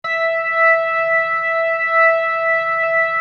B3LESLIE E 6.wav